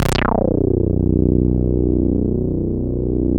Index of /90_sSampleCDs/InVision Interactive - Keith Emerson - The Most Dangerous Synth and Organ/ORGAN+SYNTH1
23-SAWRESDRY.wav